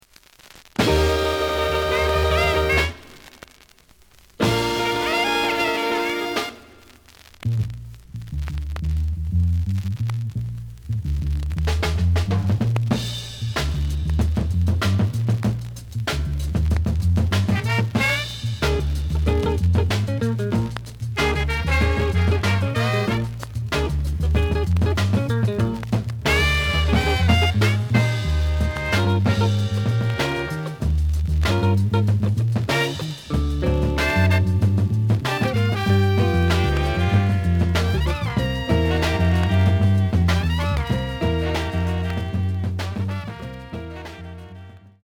The audio sample is recorded from the actual item.
●Genre: Funk, 60's Funk
Some click noise on B side due to scratches.)